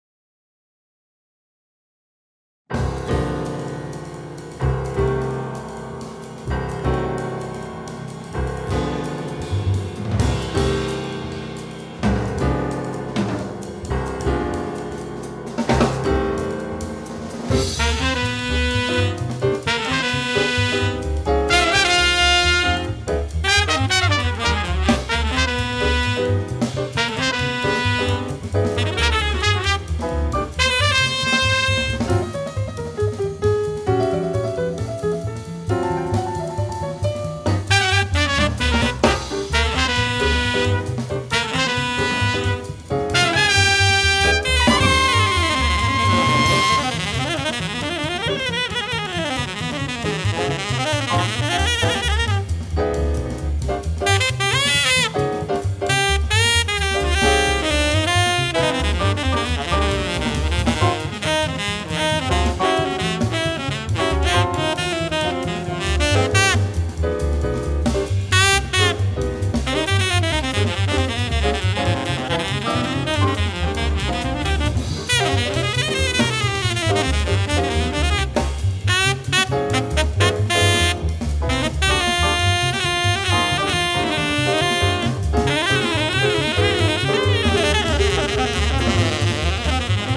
klavir
kontrabas
bobni